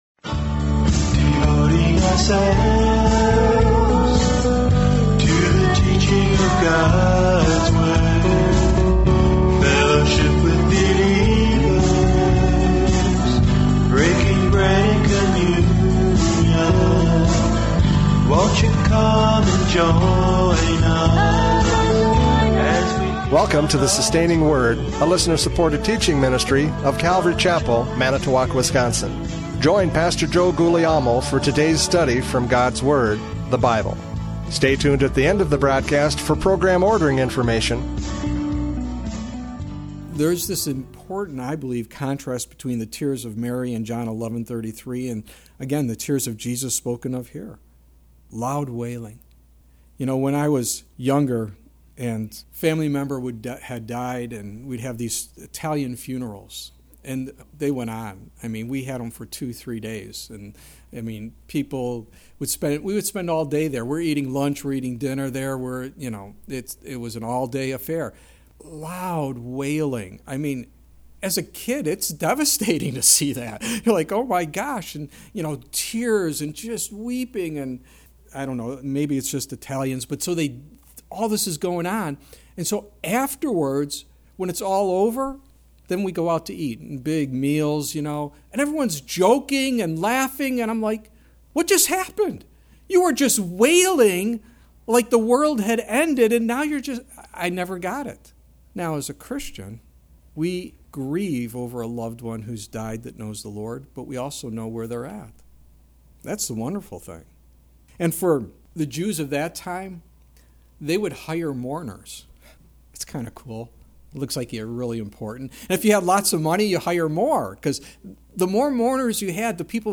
John 11:28-44 Service Type: Radio Programs « John 11:28-44 “But Lord